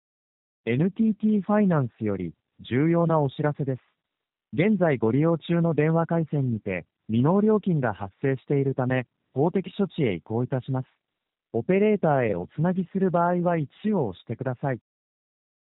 音声ガイダンスを利用した特殊詐欺の電話音声
ＮＴＴファイナンス を装った音声ガイダンス（MP3：253KB）